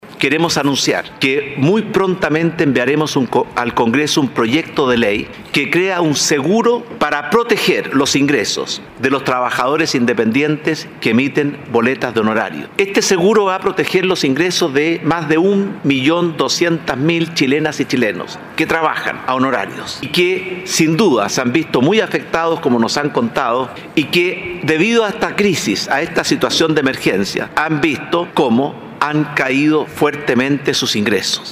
El Presidente de la República, Sebastián Piñera, acompañado por los ministros del Trabajo, María José Zaldívar, y de Hacienda, Ignacio Briones, presenta el proyecto de ley que crea un seguro de protección de ingresos para trabajadores independientes. La iniciativa entregará beneficios a quienes emiten boletas de honorarios y busca emular la protección del Seguro de Cesantía.